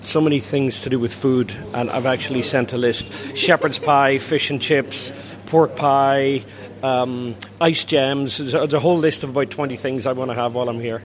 At City of Derry Airport, the scene of Highland Radio’s ‘Home for Christmas’ reunion earlier this week, the next few days are expected to be the busiest.